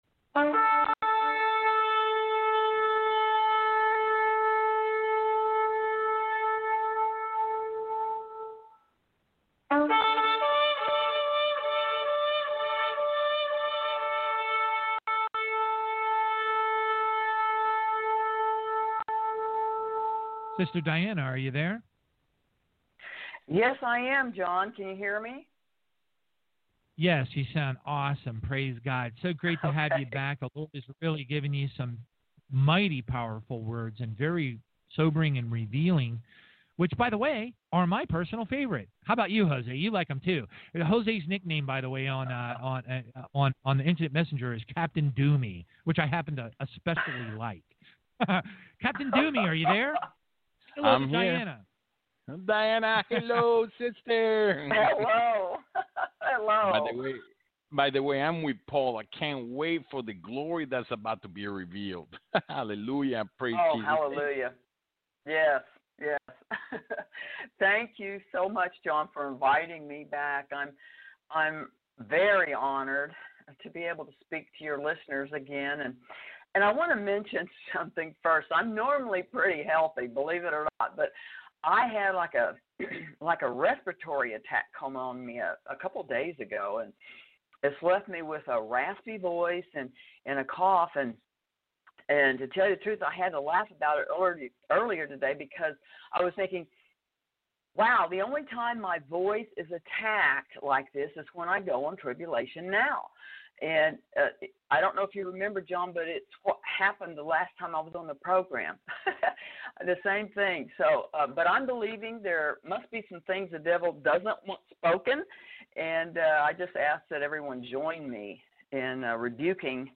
This interview was given on Tribulation-Now in May, 2019. The Lord’s shown what’s just ahead, along with specific instructions to His people.